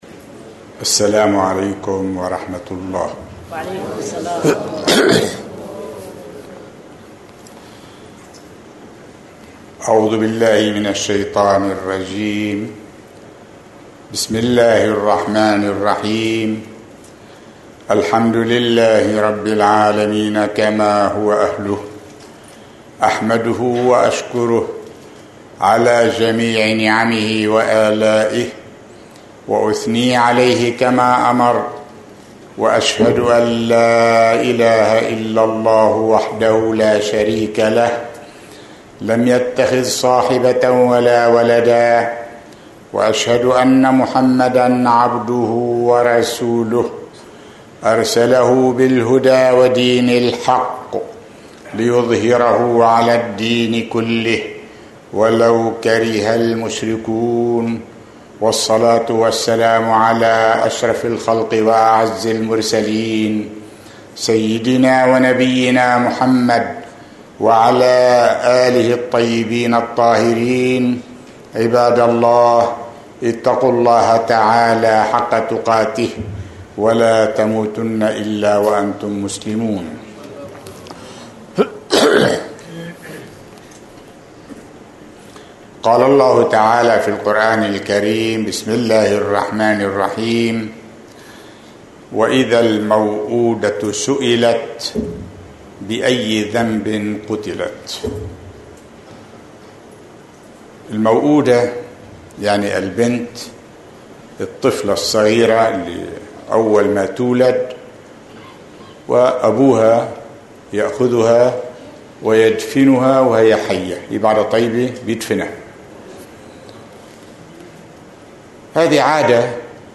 خطبة الجمعة
في المؤسسة الإسلامية الاجتماعية في دكار